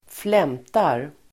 Uttal: [²fl'em:tar]